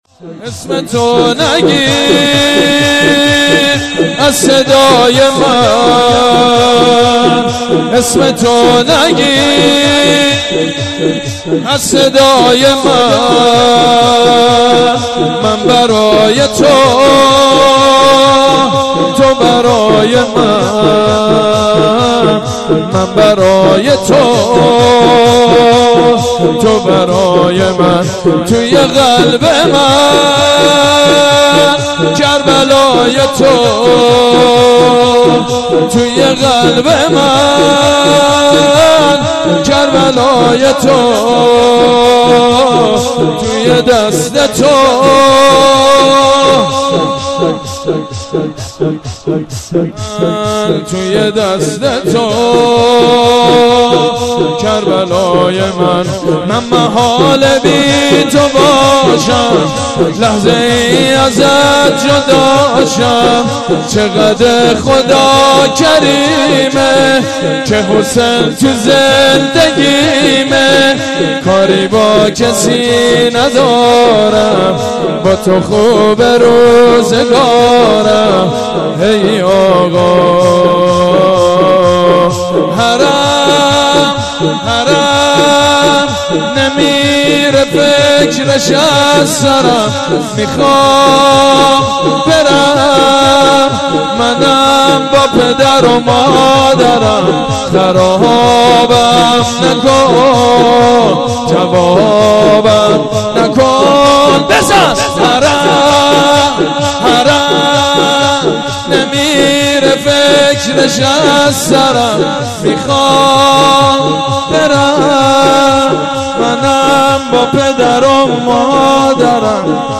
مناسبت : وفات حضرت ام‌البنین سلام‌الله‌علیها
قالب : شور